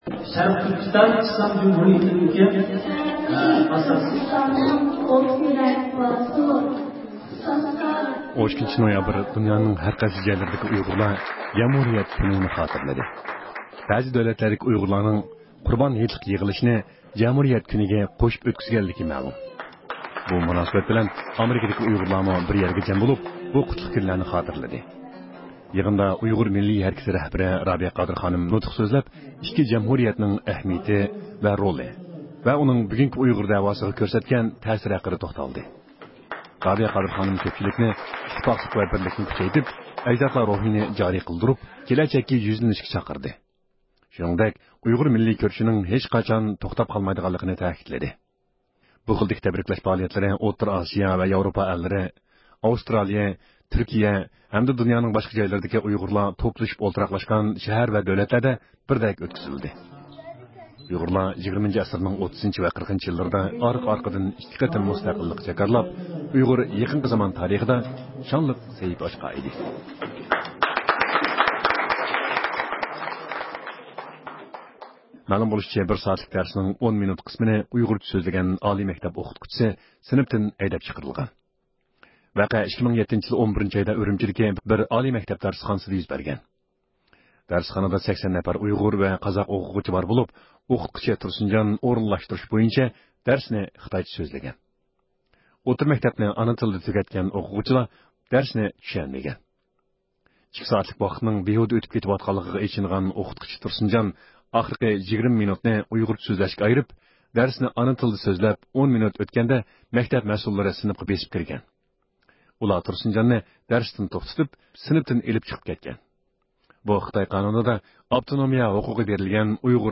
ھەپتىلىك خەۋەرلەر (12-نويابىردىن 18-نويابىرغىچە) – ئۇيغۇر مىللى ھەركىتى